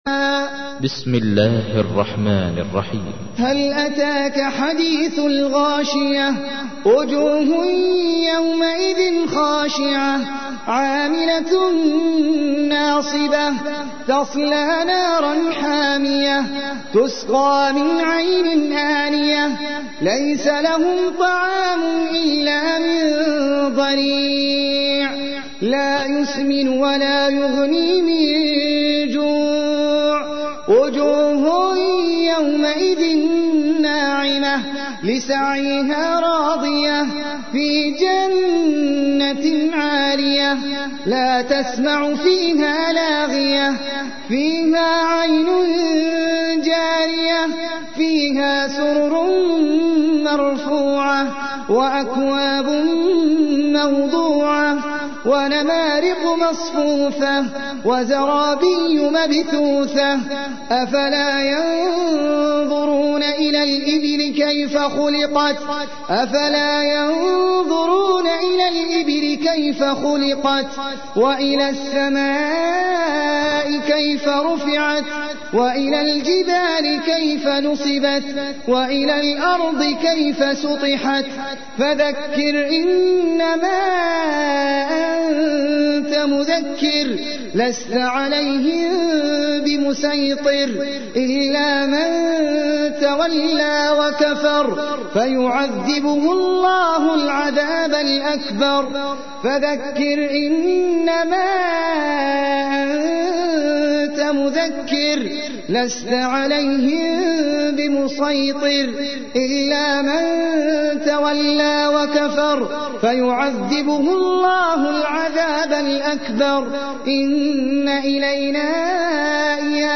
تحميل : 88. سورة الغاشية / القارئ احمد العجمي / القرآن الكريم / موقع يا حسين